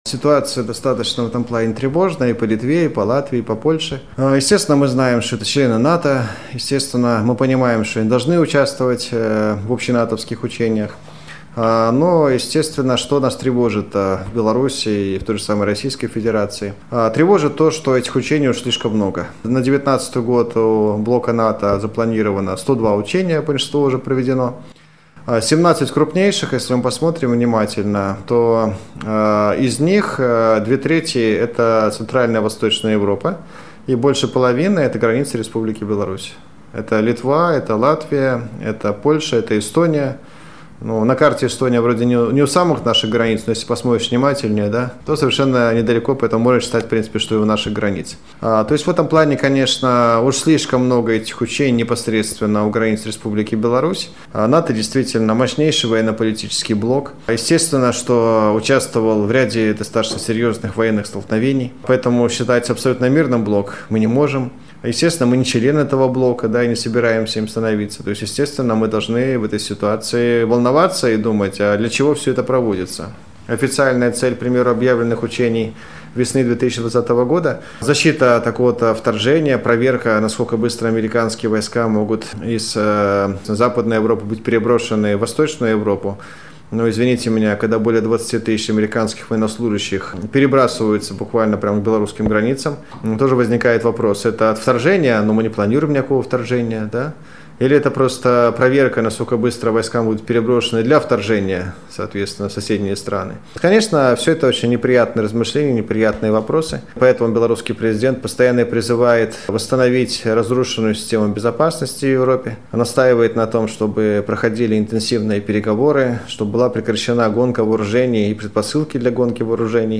Мнение эксперта.